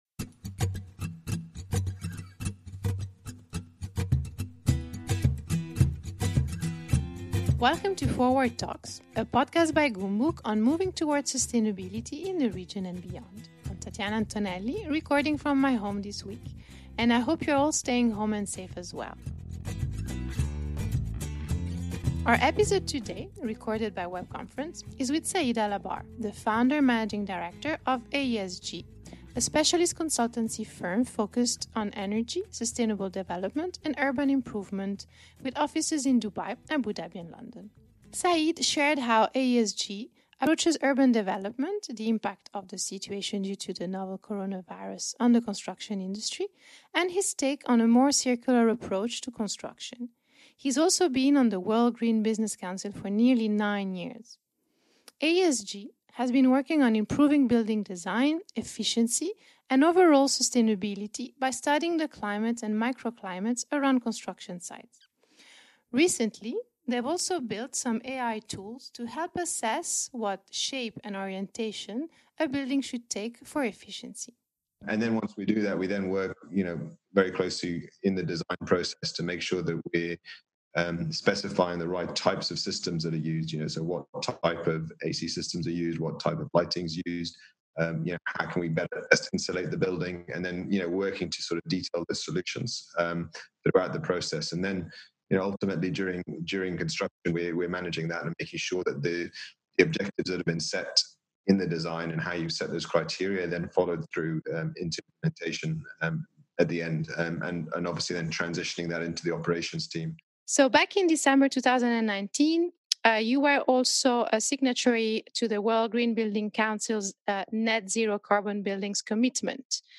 We're joined this week via web conference